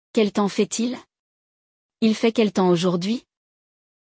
Durante a aula, você ouvirá um diálogo entre dois amigos conversando sobre o tempo.